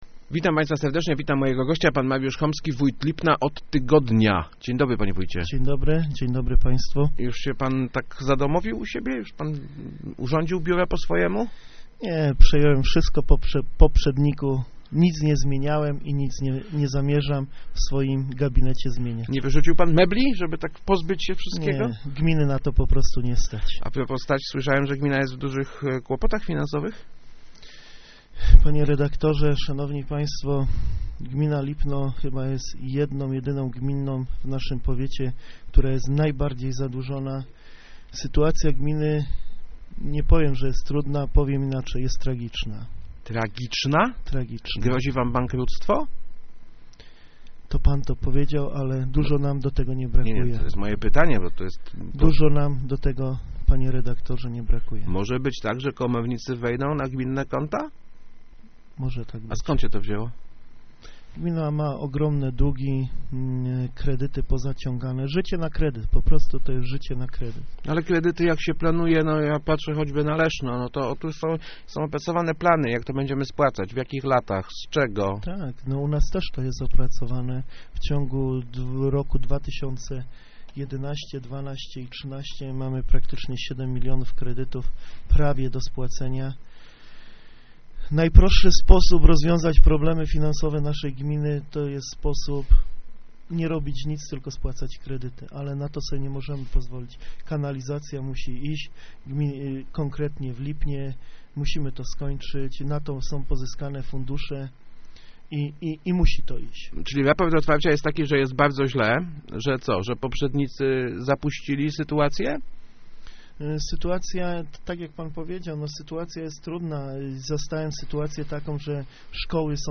Sytuacja finansowa gminy jest katastrofalna - mówił w Rozmowach Elki Mariusz Homski, nowy wójt Lipna.